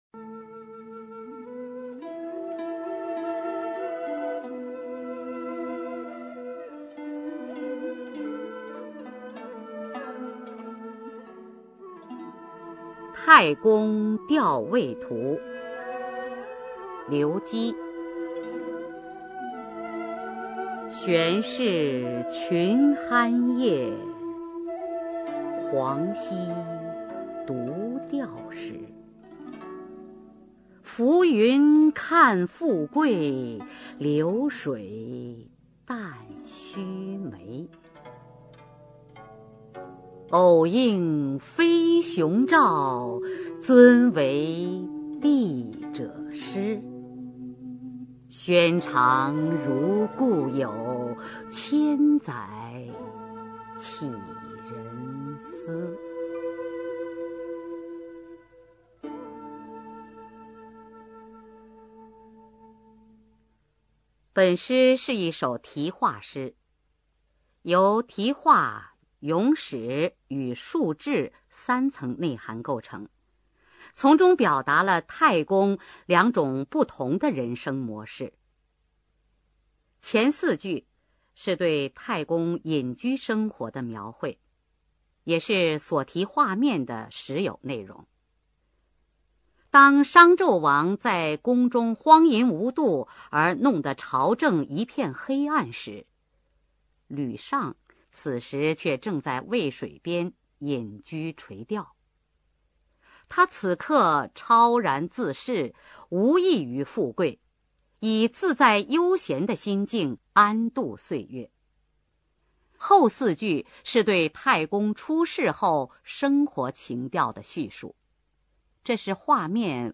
[明代诗词诵读]刘基-太公钓渭图 朗诵